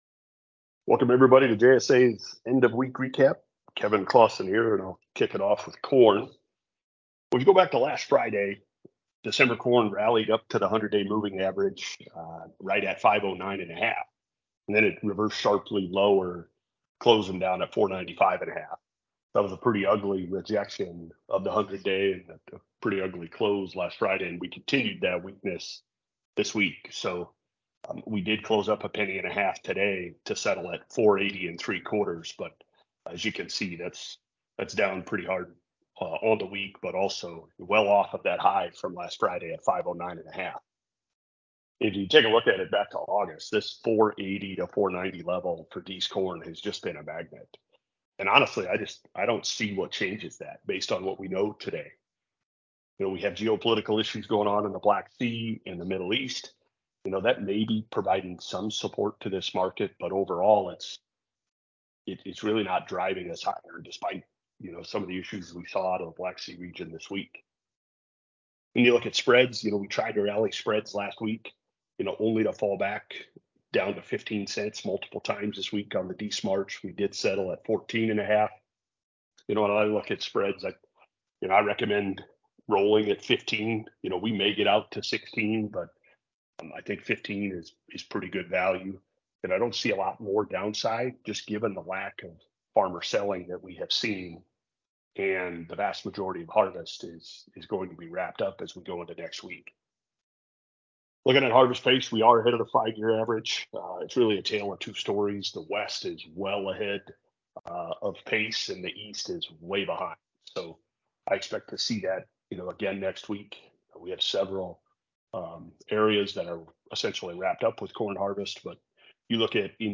JSA Weekly Conference Call (10/27/2023)